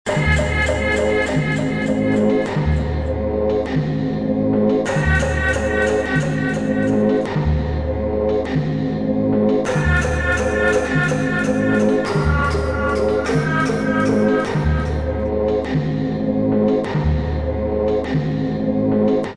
Sound demo